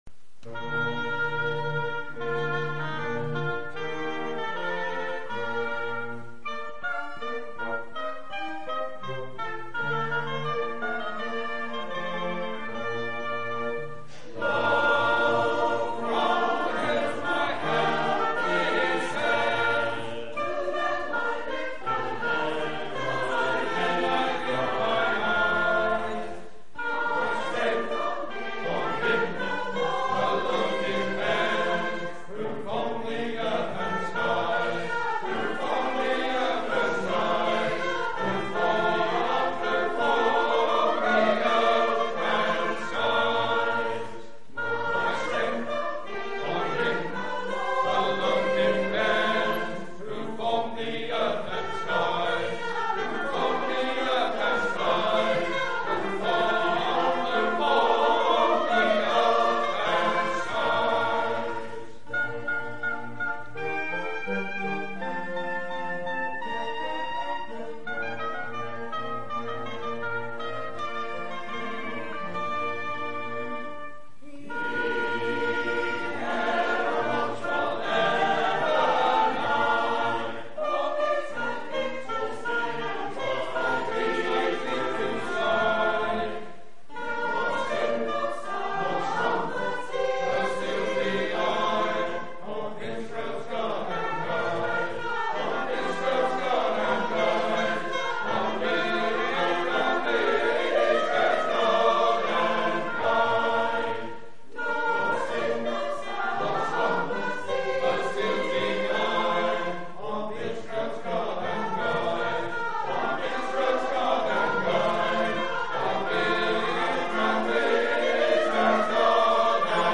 Henry Tolhurst (1778-1814), A Kent Composer of Church Music for Country Choirs.